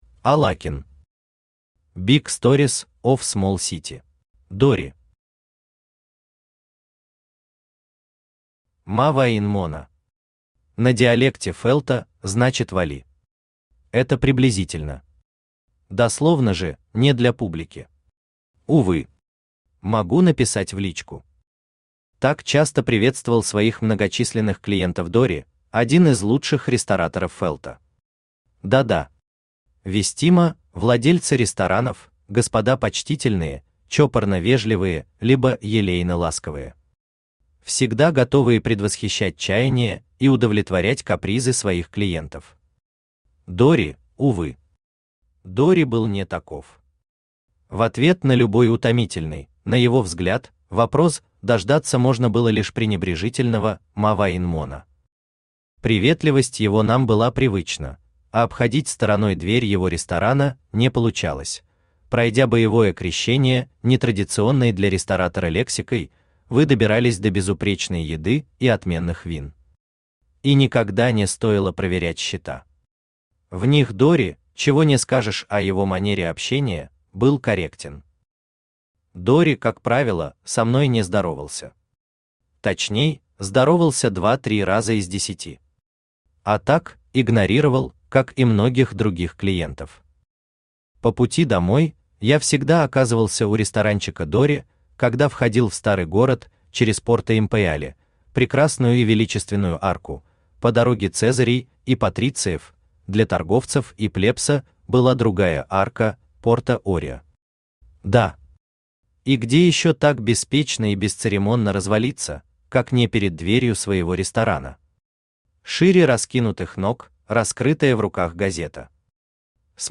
Aудиокнига Big stories of small city Автор ALAKIN Читает аудиокнигу Авточтец ЛитРес. Прослушать и бесплатно скачать фрагмент аудиокниги